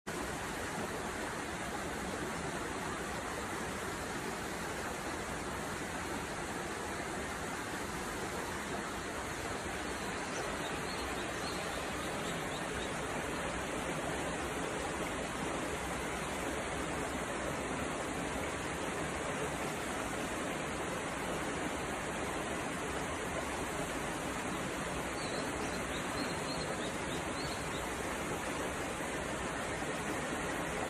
川のせせらぎ、鳥の声が聞こえる。